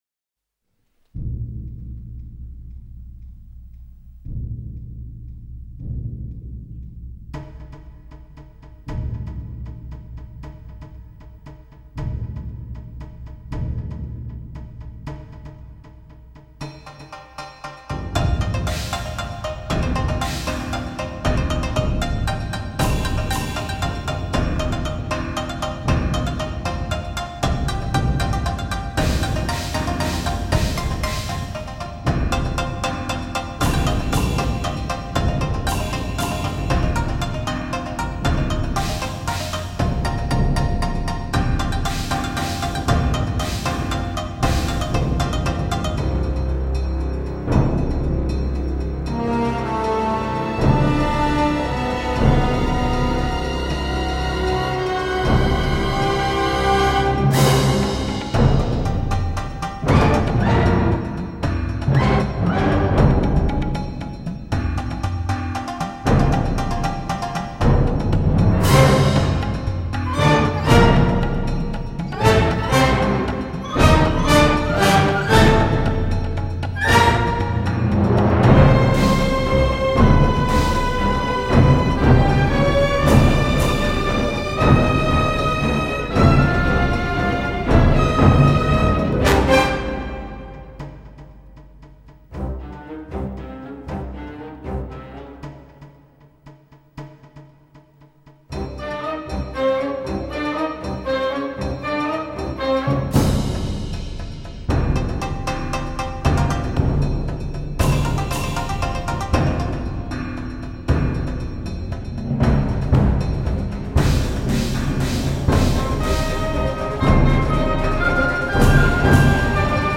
气势磅礴,扣人心弦的电影原声乐及主题曲